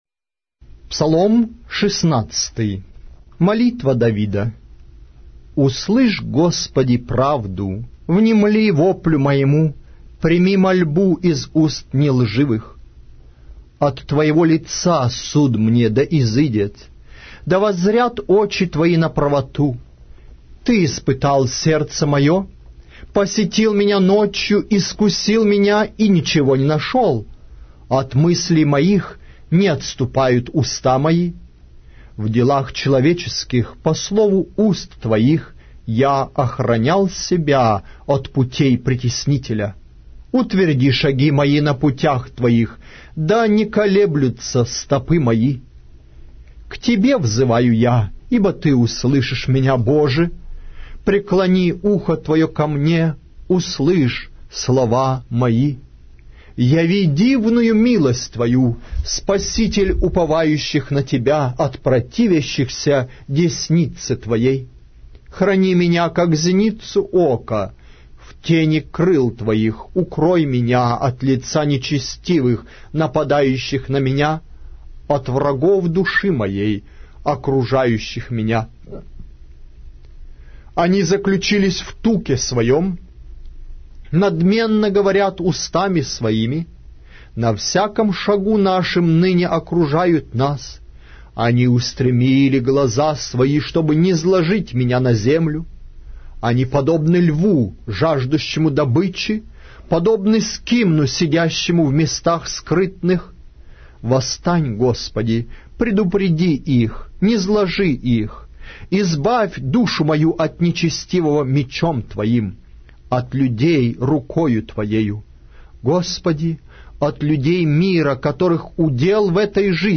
Аудиокнига: Псалтирь